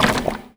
R - Foley 184.wav